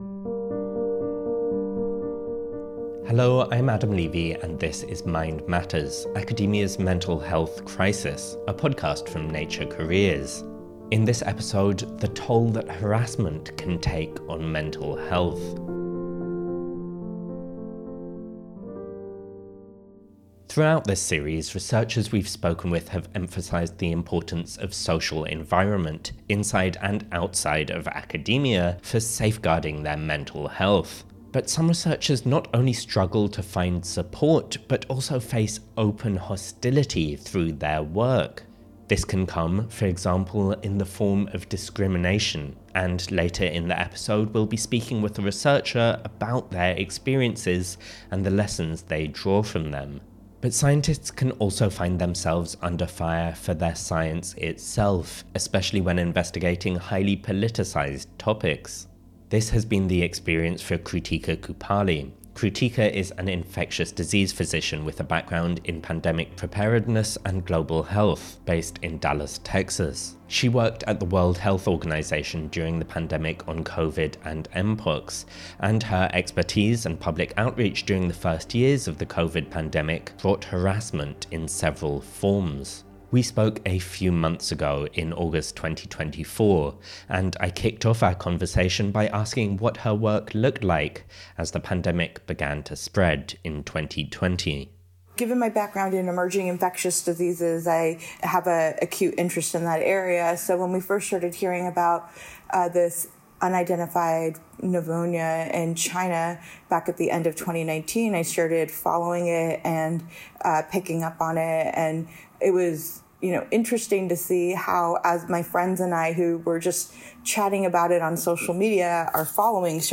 talks to two scientists whose mental health suffered after facing personal attacks.